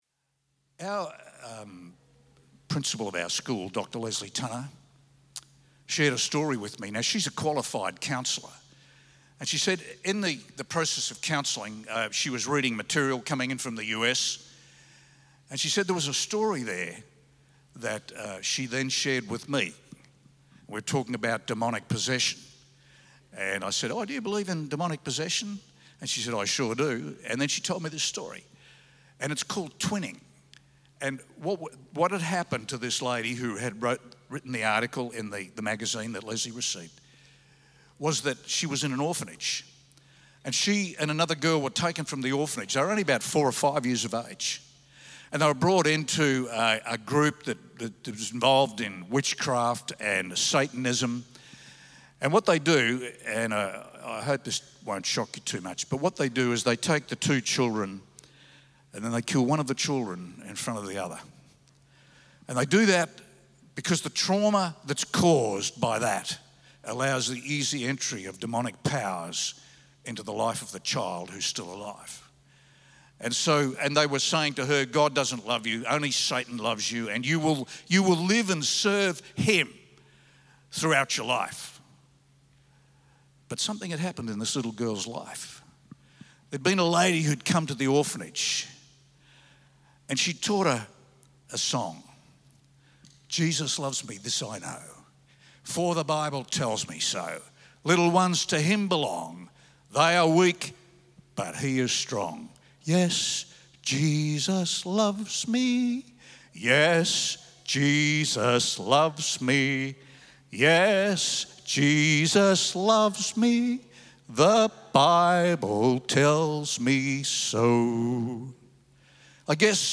Sermons | Mackay Christian Family